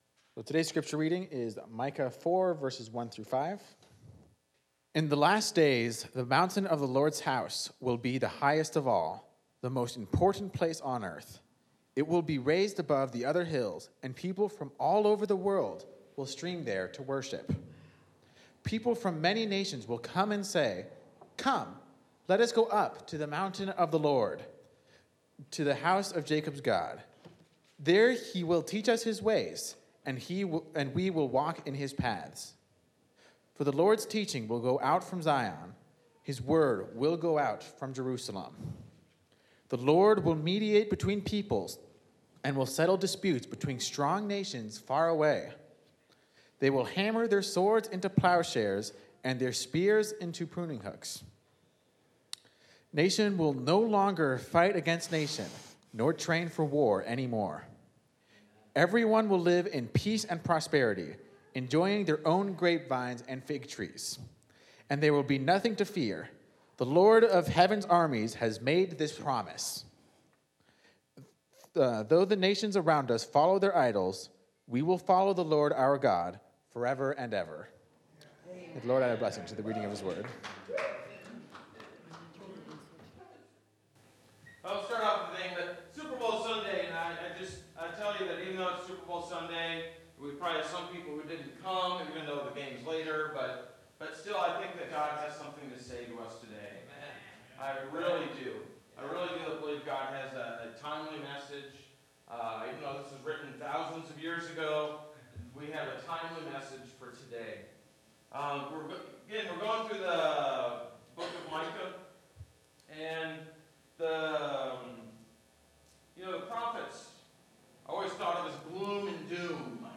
Bible Text: Micah 4:1-5 | Preacher